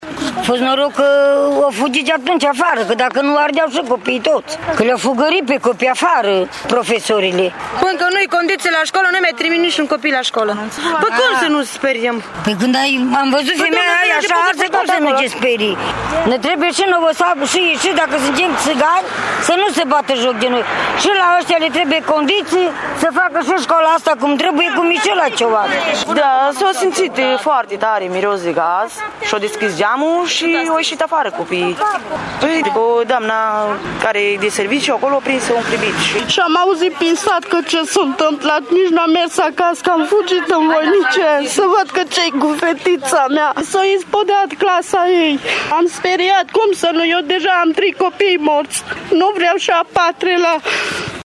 Explozia a produs panică între elevi, dar și între părinții acestora: